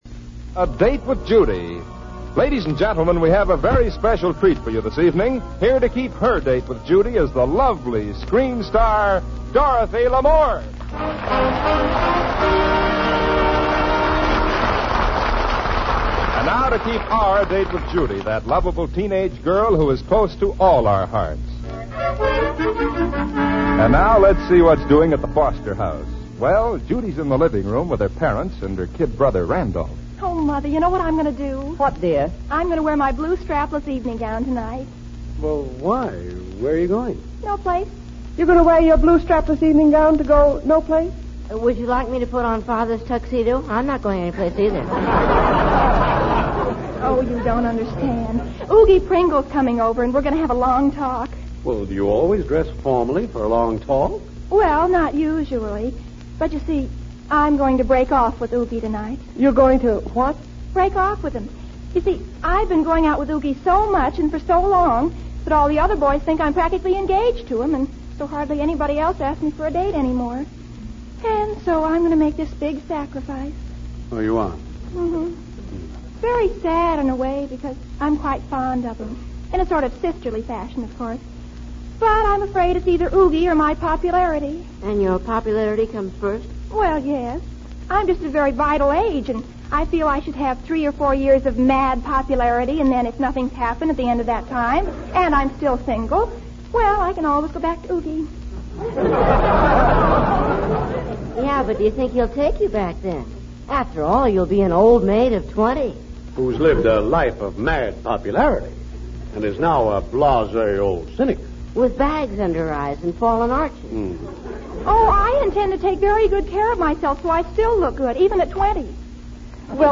A Date with Judy Radio Program